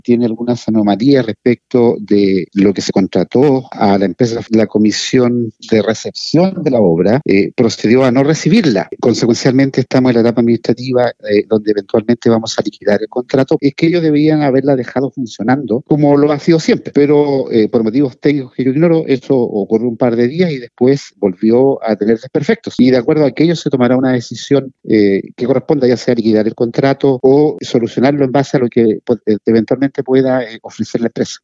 Al respecto el alcalde subrogante de Osorno, Hardy Vásquez, informó que se detectaron algunas anomalías y por eso la comisión de recepción resolvió no recibir los trabajos.